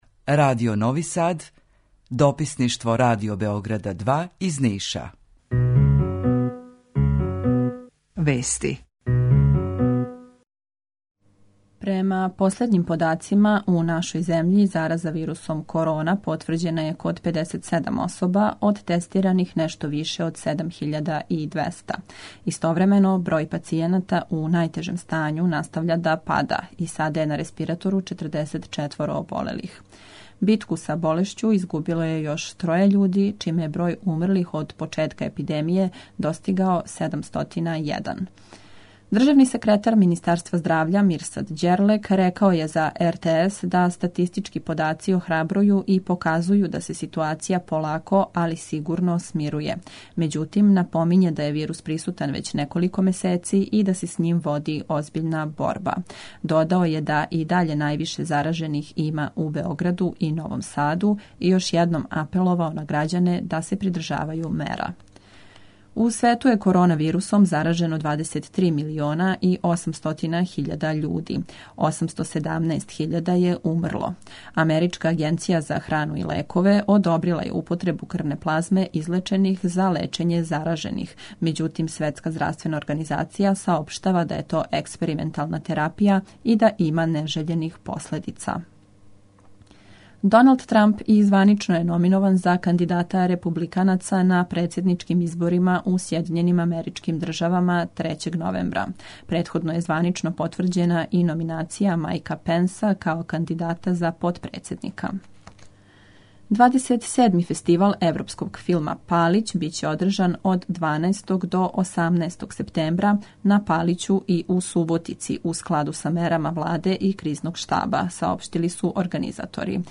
Јутарњи програм из три студија